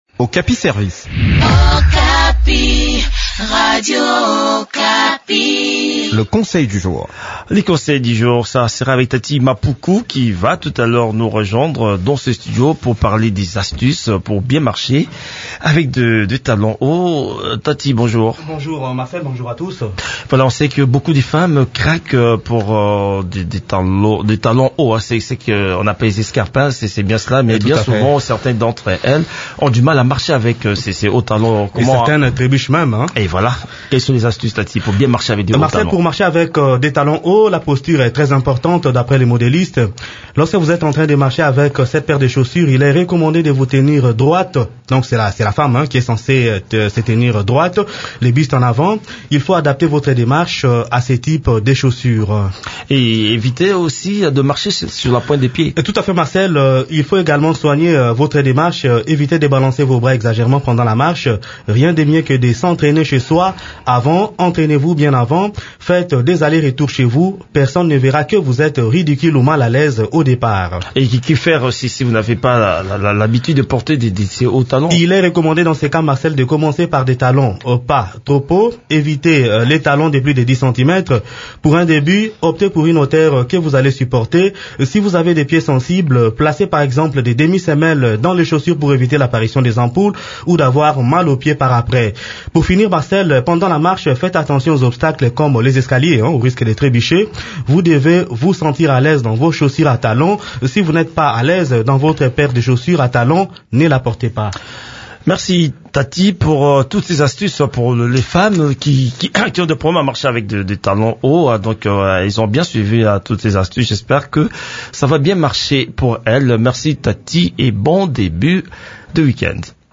Découvrez quelques conseils utiles pour marcher sereinement avec des talons hauts dans cette chronique